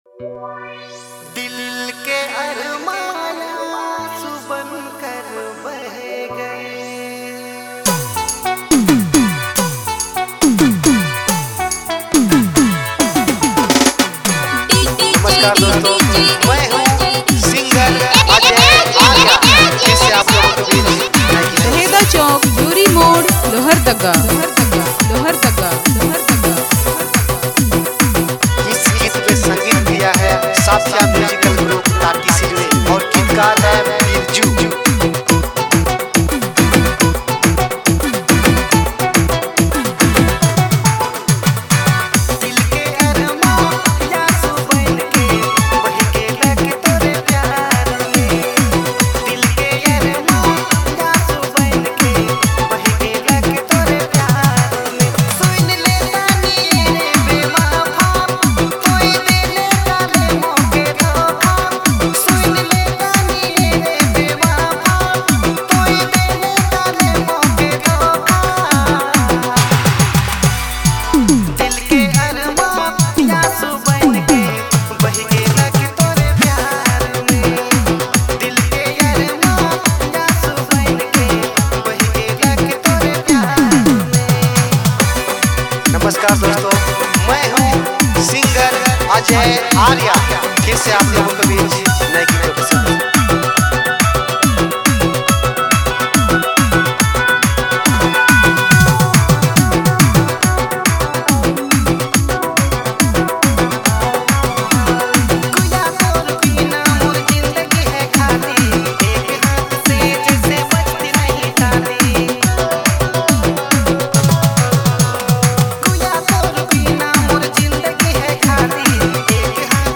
is an emotional yet energetic Nagpuri remix
powerful DJ remix